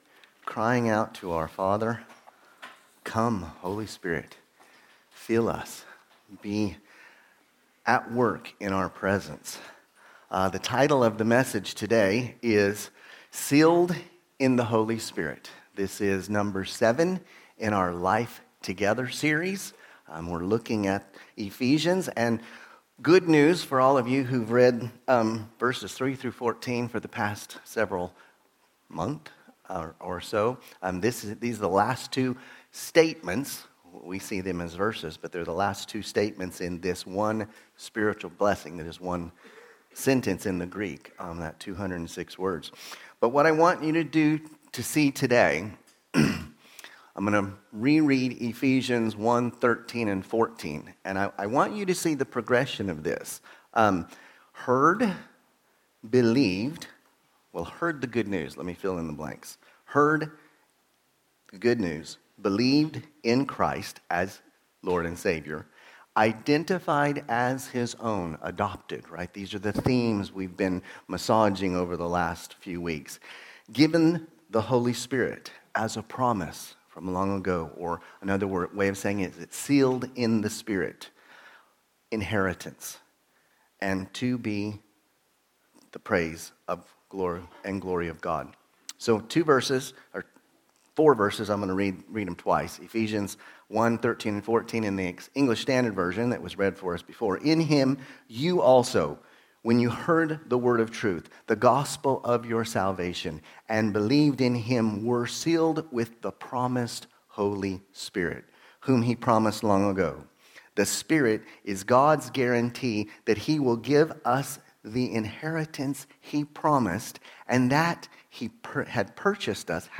Sermons – Immanuel Baptist Church | Madrid